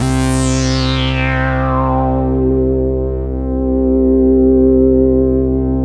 SYN_JD-800 1.4.wav